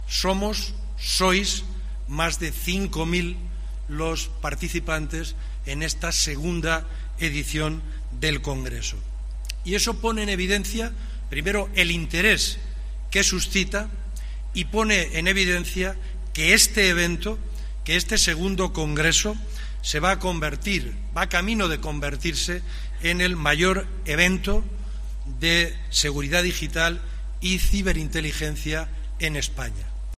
director general de la Policía, Francisco Pardo
Lo ha destacado, el director general de la Policía, Francisco Pardo en su ponencia inicial.